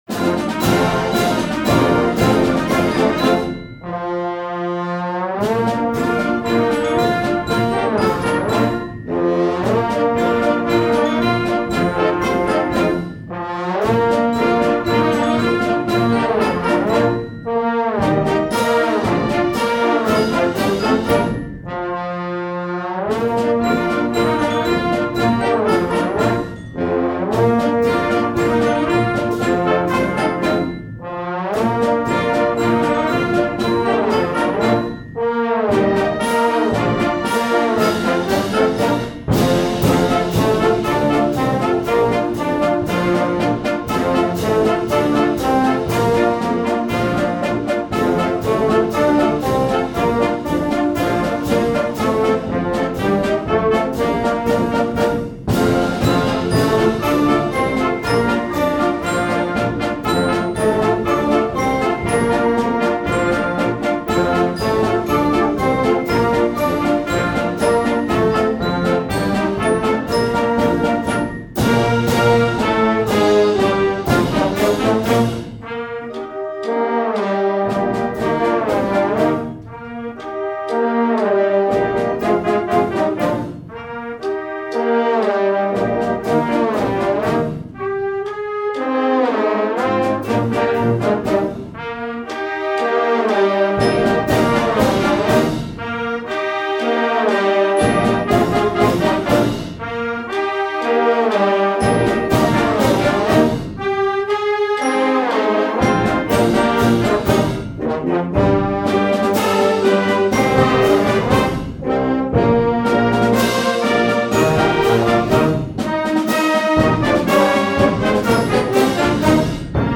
Voicing: Trombone Section w/ Band